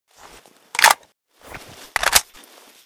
ak74_reload.ogg